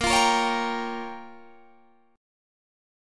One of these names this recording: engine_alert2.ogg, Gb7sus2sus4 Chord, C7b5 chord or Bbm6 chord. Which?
Bbm6 chord